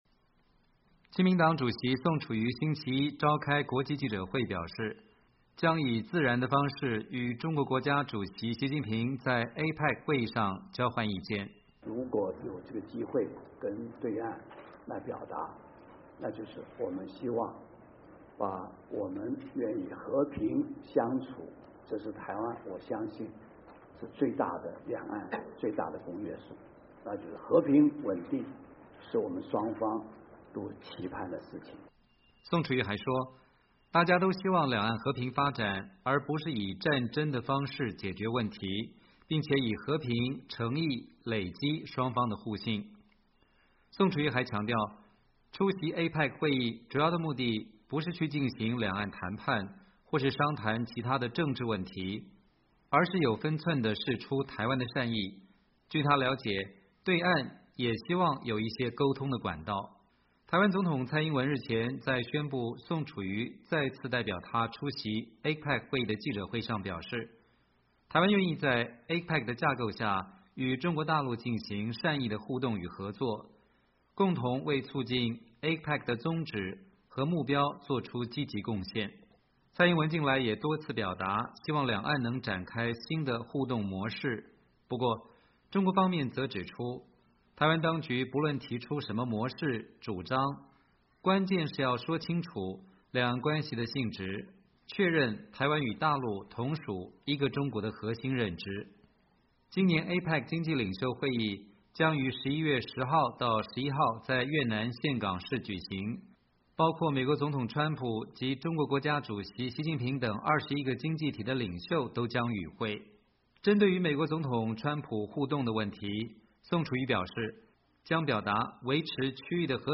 台湾亲民党主席宋楚瑜召开出席APEC行前国际记者会 （2017年11月6日）
台湾立法院内政委员会委员、在野党国民党立委黄昭顺接受美国之音采访表示，台湾民众都希望两岸的僵局能够化解，不过，宋楚瑜到底能和习近平谈到什么程度则令人质疑。